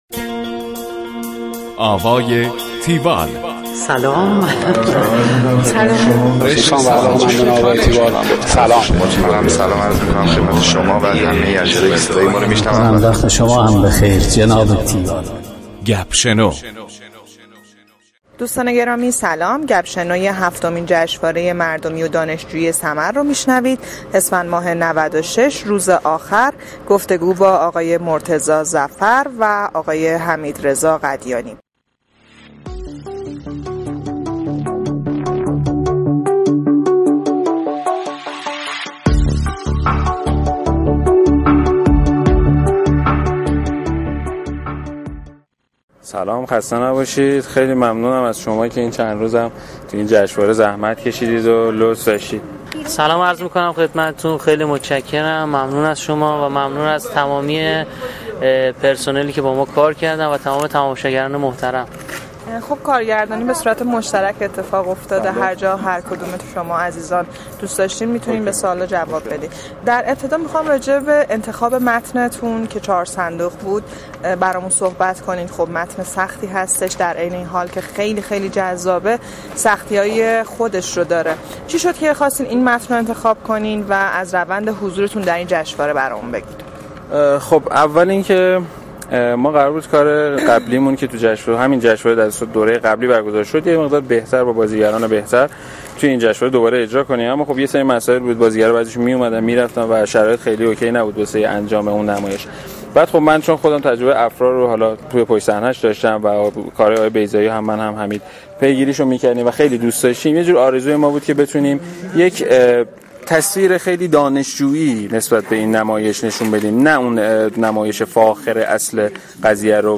tiwall-interview-4sandogh.mp3